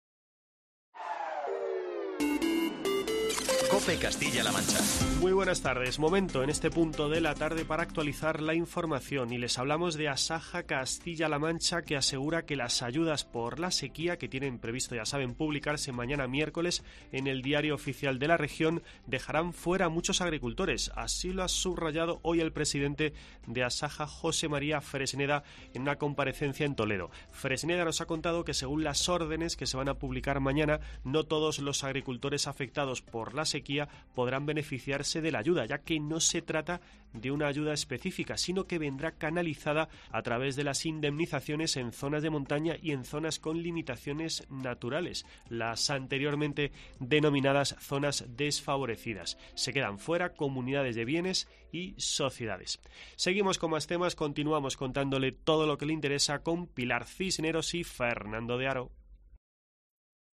17:00 H | 10 OCT 2025 | BOLETÍN